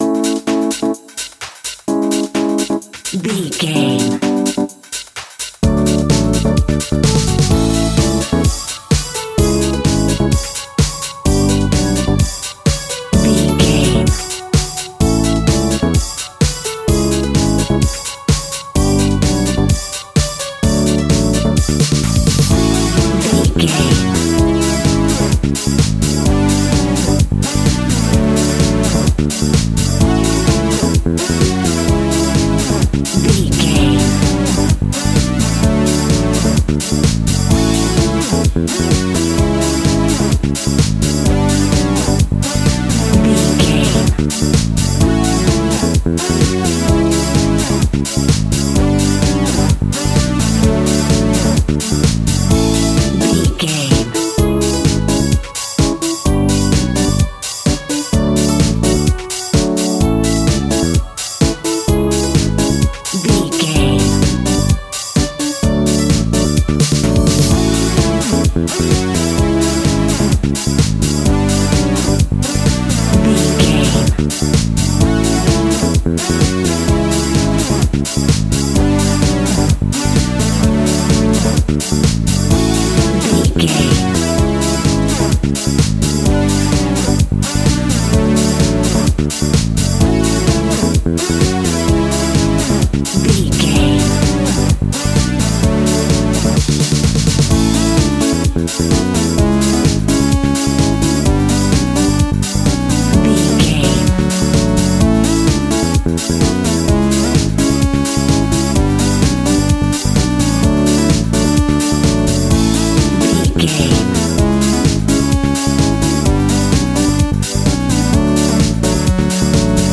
Funky House Cheese.
Ionian/Major
D
groovy
dreamy
smooth
drum machine
synthesiser
disco
upbeat
electric guitar
clavinet
synth bass
horns